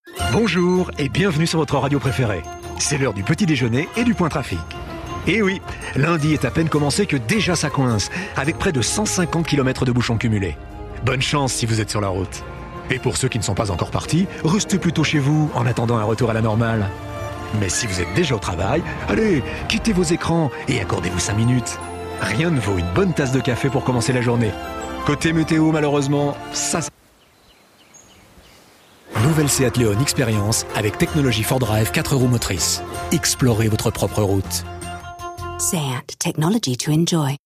Genre : Voix-Off